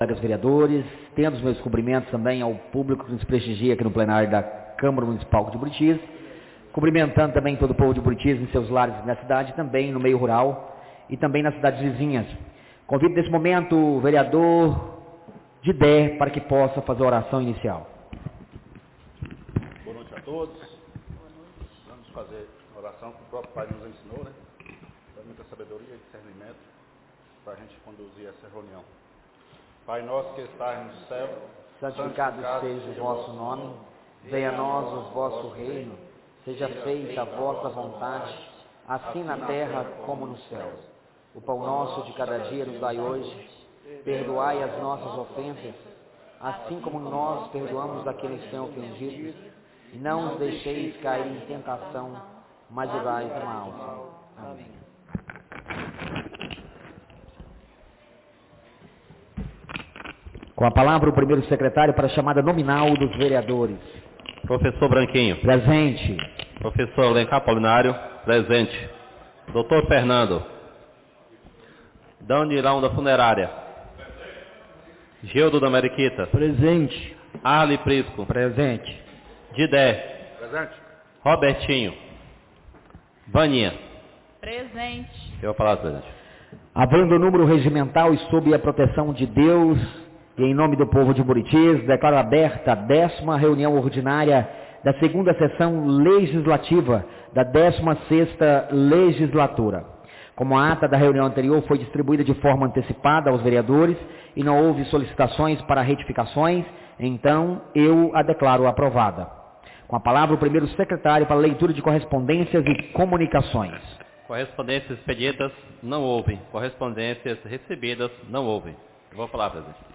10ª Reunião Ordinária da 2ª Sessão Legislativa da 16ª Legislatura - 06-04-26